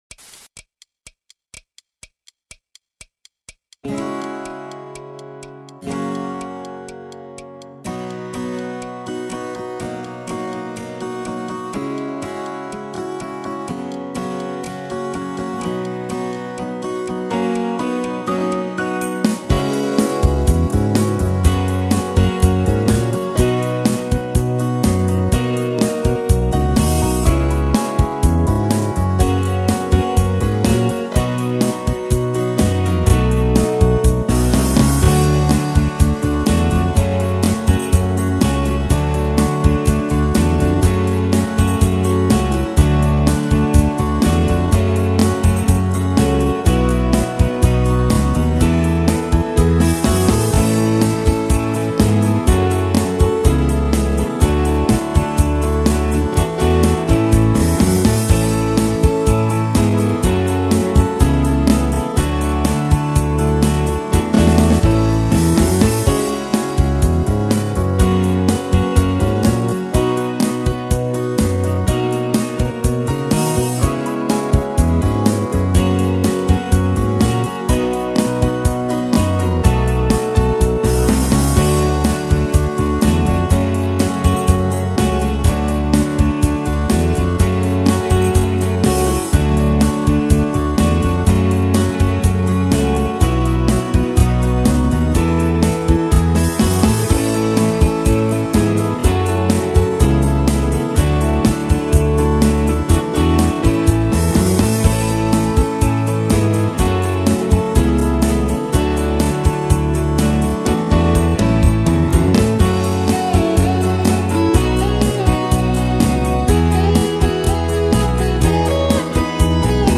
Hier kun je een versie in wat hogere toonsoort beluisteren: